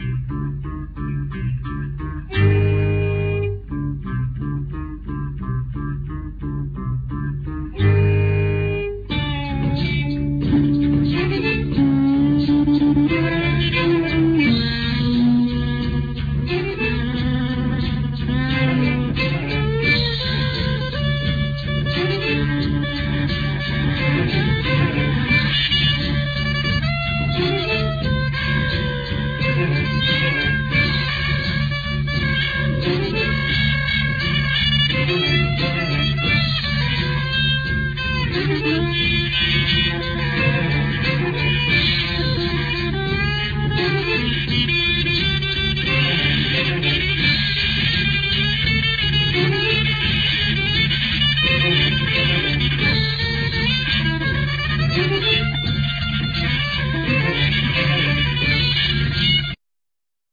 Bass, Vocal
Klaviphone, Vibraphone, Guitar, Vocal
Violin, Vocal
Altsax
Drums
Flute
Theremin